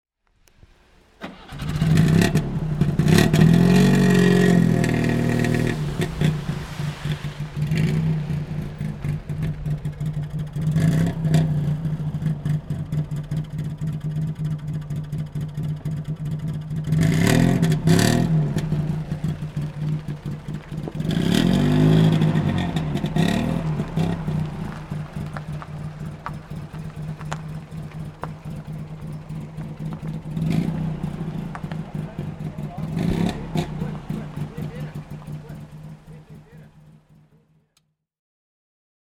• Two cylinders
Panhard CD (1964) - Starten und Leerlauf